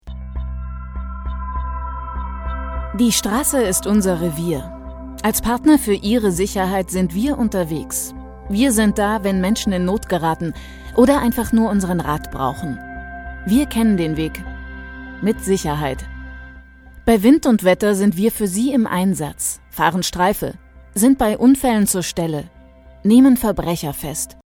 deutsche Sprecherin,Hörspiel,Computerspiele,Werbung,Voice over,Imagefilm,Hörbuch variabel von rauchig dunkel bis spritzig frisch, sexy.
Sprechprobe: Industrie (Muttersprache):